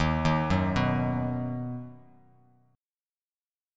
D#m#5 chord